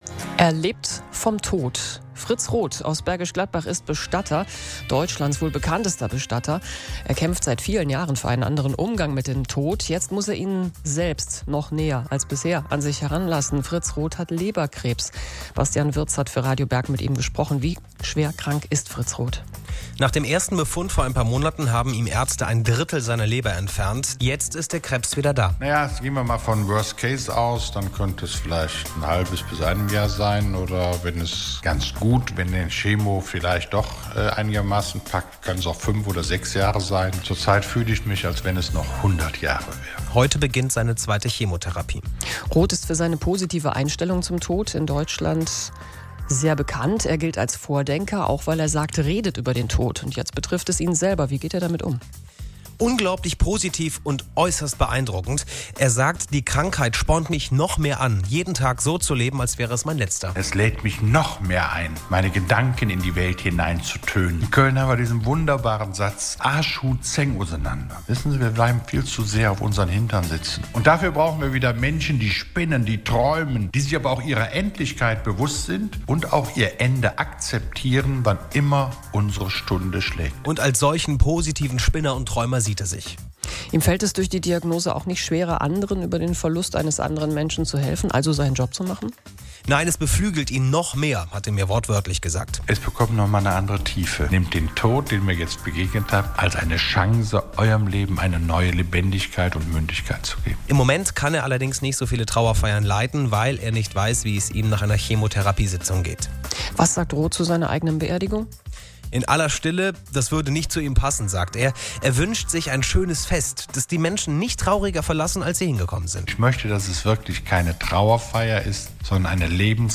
radioberginterview2012.mp3